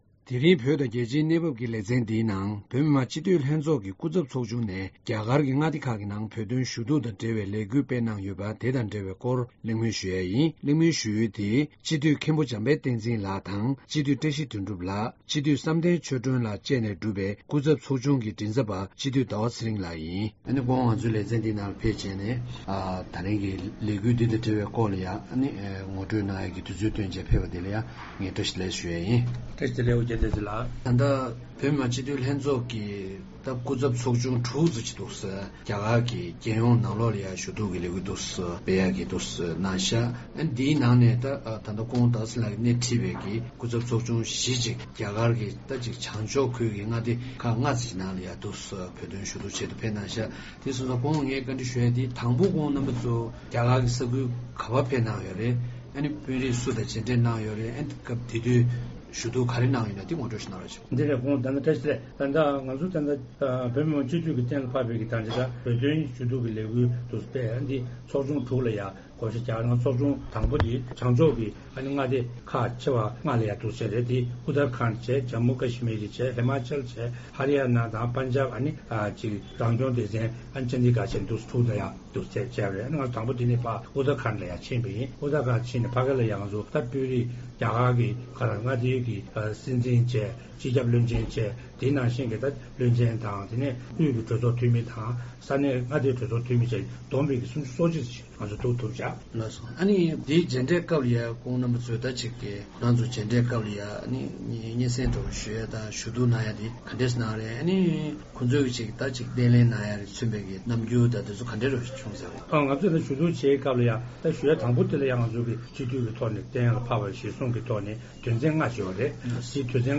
བོད་དང་རྒྱལ་སྤྱིའི་གནས་བབ་ཞེས་པའི་ལེ་ཚན་ནང་། བོད་མི་མང་སྤྱི་འཐུས་ལྷན་ཚོགས་ཀྱི་སྐུ་ཚབ་ཚོགས་ཁག་ནས་རྒྱ་གར་གྱི་མངའ་སྡེ་ཁག་ནང་བོད་དོན་ཞུ་གཏུགས་ཀྱི་ལས་འགུལ་སྤེལ་ཡོད་པའི་སྐོར་འབྲེལ་ཡོད་དང་གླེང་མོལ་ཞུས་པ་གསན་རོགས་གནང་།